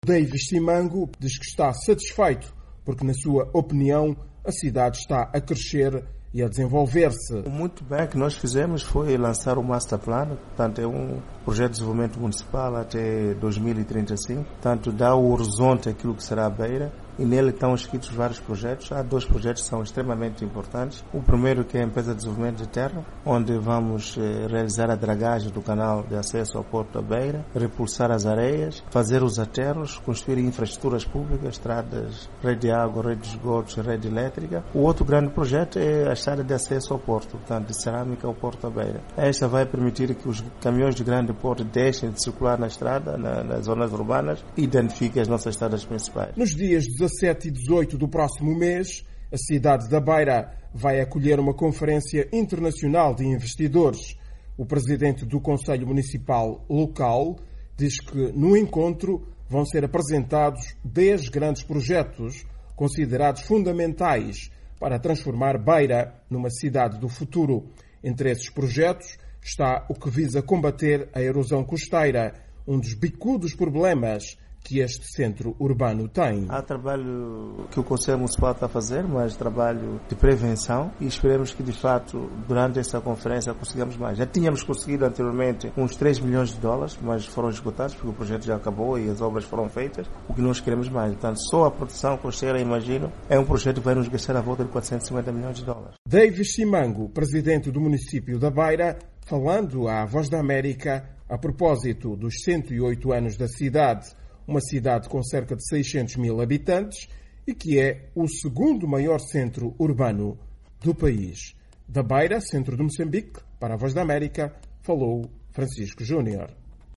Daviz Simango falava hoje à VOA a proposito da celebracao do centesimo oitavo aniversario de elevacao da Beira à categoria de cidade...